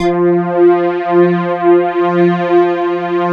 SYN JX49 08L.wav